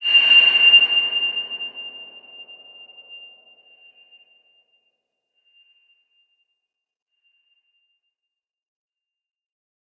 X_BasicBells-F5-ff.wav